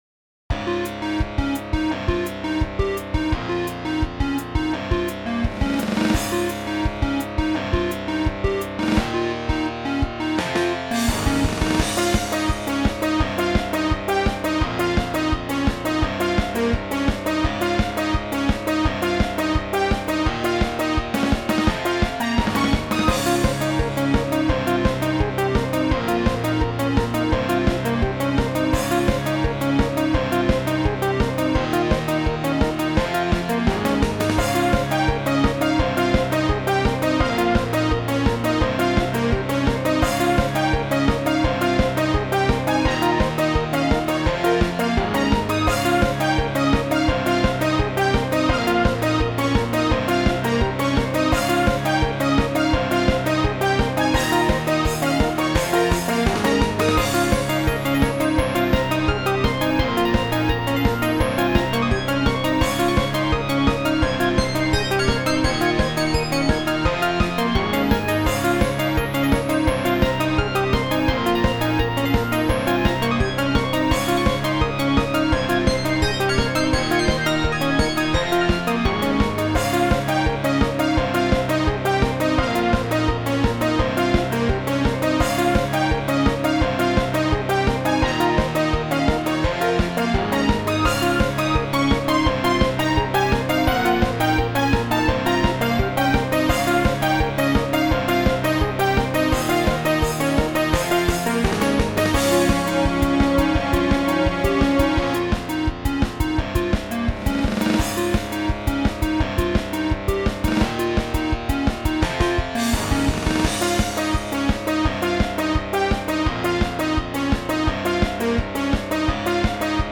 ジャンルTechno(とかのつもり)
説明テクノ系の曲を作りたかった